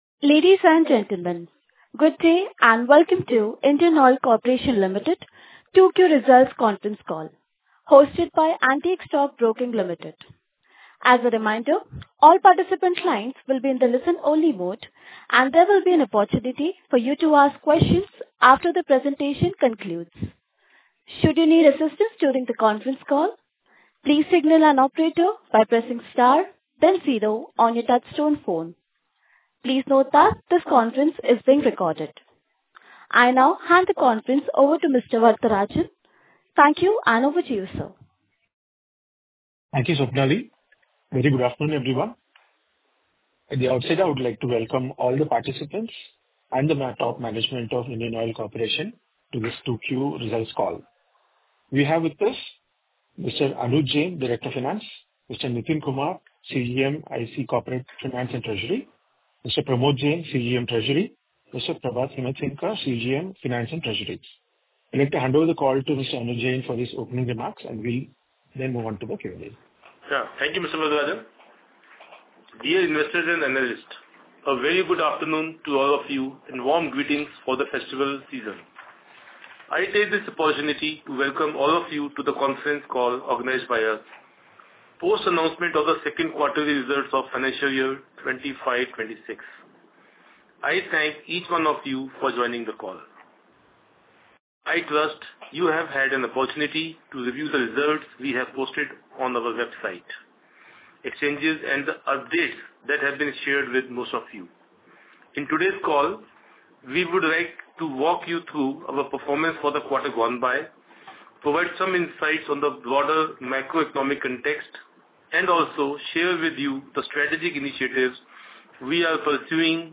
Conference call with Analysts | IndianOil for Investors | IndianOil